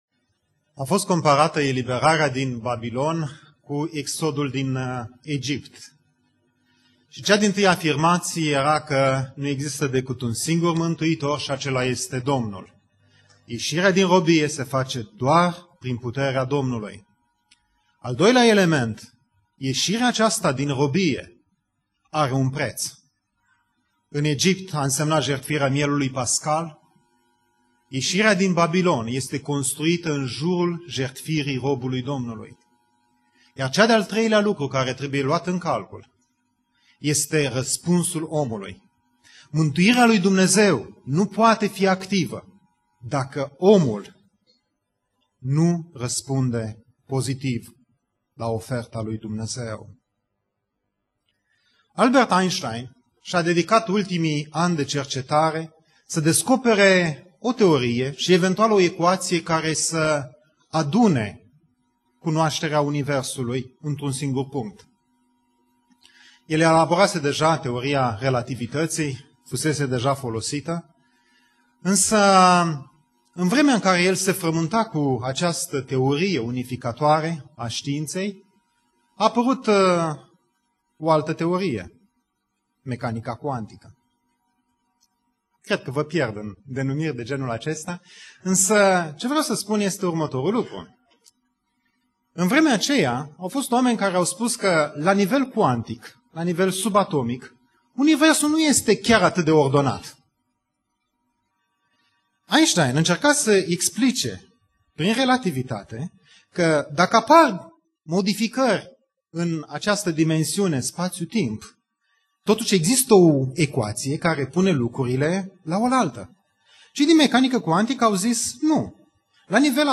Predica Aplicatie - Isaia 55-57